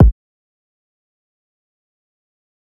PS - Siz Kick.wav